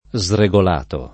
vai all'elenco alfabetico delle voci ingrandisci il carattere 100% rimpicciolisci il carattere stampa invia tramite posta elettronica codividi su Facebook sregolato [ @ re g ol # to ] part. pass. di sregolare e agg.